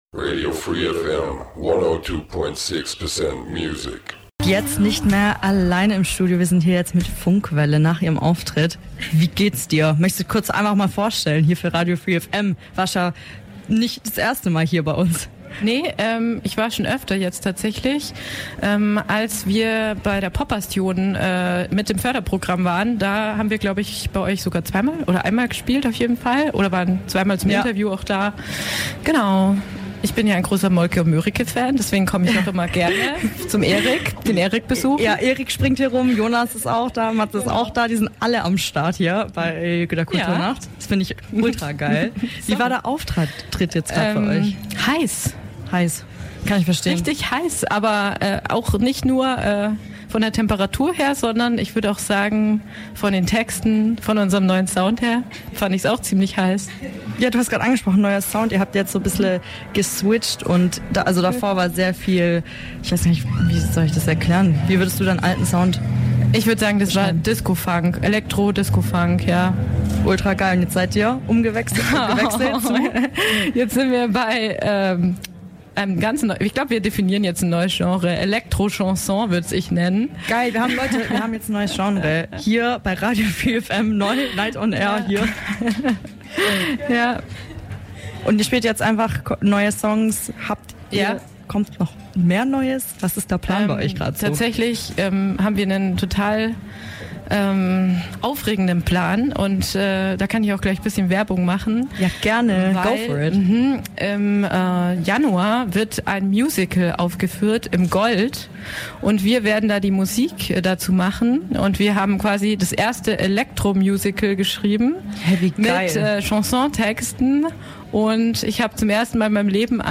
Night On Air Interview mit DaDaGegen
Während der Night on Air 2025 haben wir vier Bands zu Gast gehabt, die nicht nur für uns gespielt haben, sondern auch Live Interviews gegeben haben!